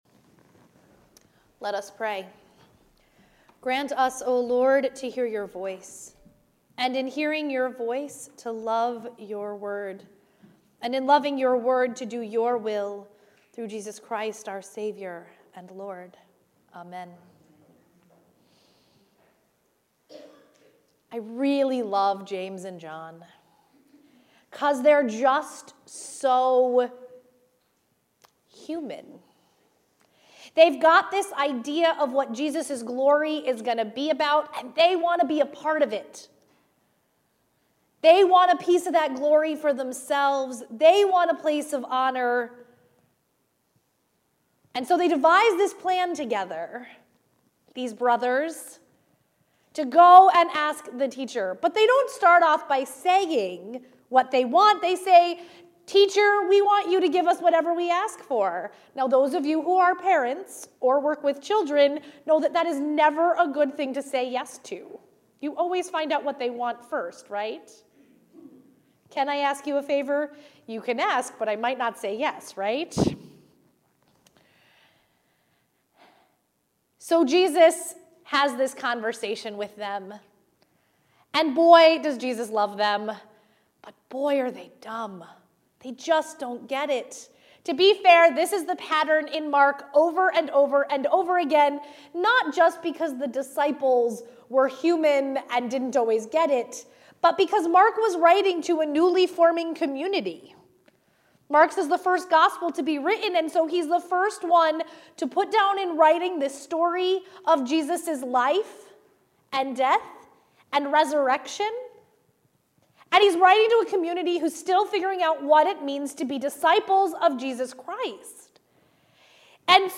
Service Type: Sunday Morning 9:30